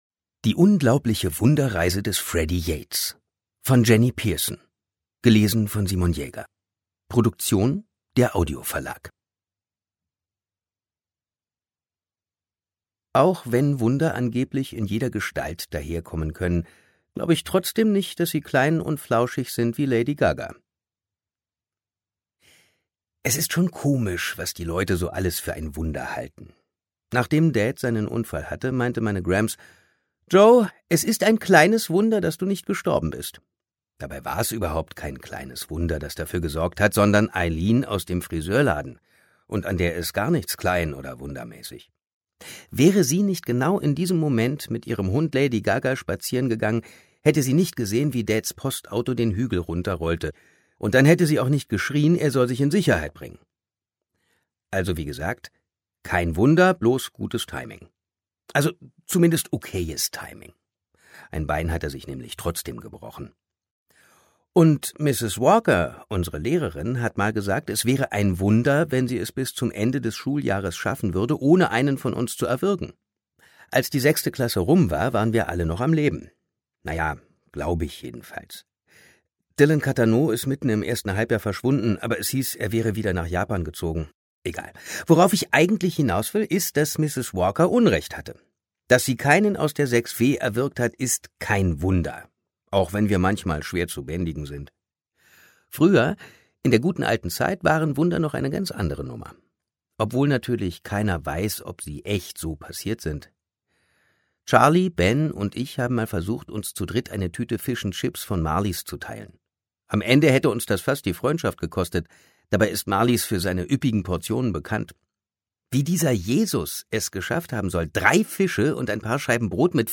Die unglaubliche Wunderreise des Freddie Yates Ungekürzte Lesung
Simon Jäger (Sprecher)